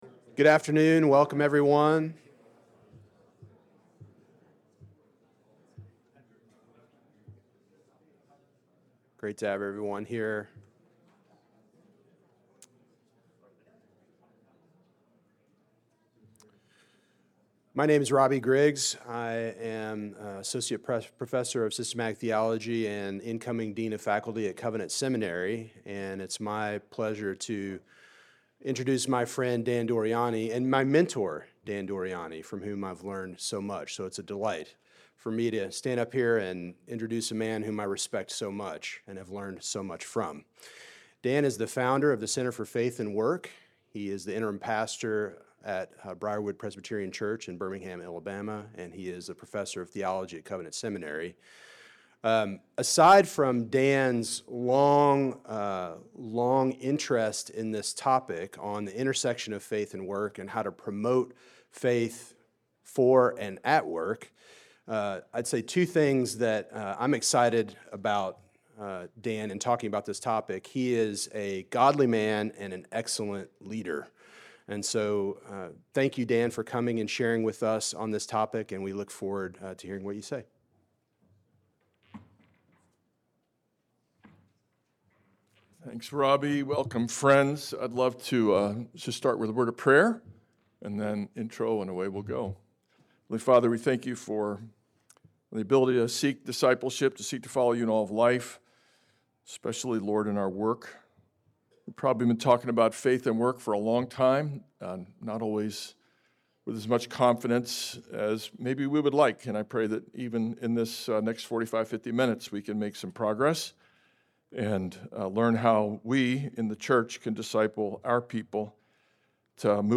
What they do every day is important to God. All honest work is also God’s work if it provides food, clothing, shelter, education, medical care, and more. This seminar will highlight the reformed view of work and provide Ruling and Teaching Elders with ways to disciple their congregations for all of life.